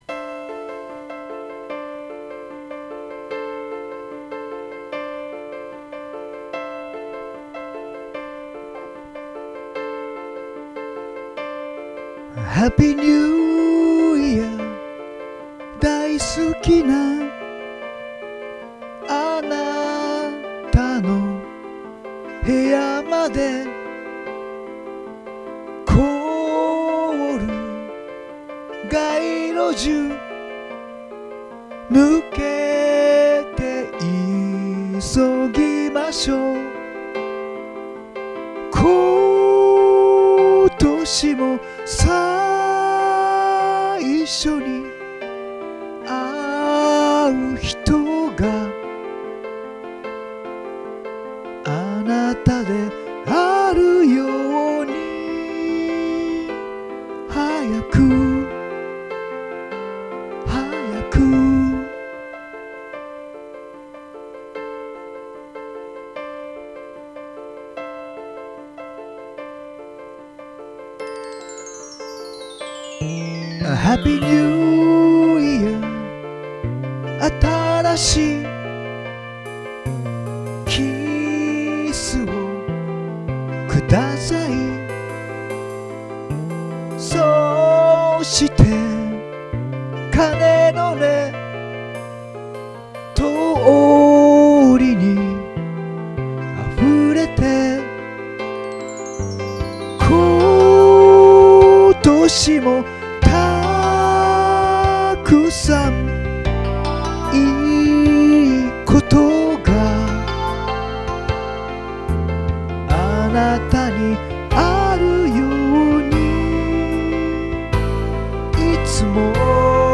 JOU SOUND でホームカラオケしたものを録音いたしまして、